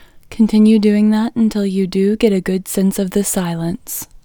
LOCATE IN English Female 32